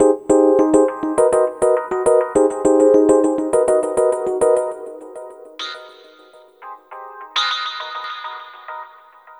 Ala Brzl 1 Fnky Piano-F.wav